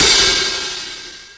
Bon Bon Open Hi-Hat OS 02.wav